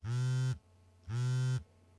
cell_ring_1.ogg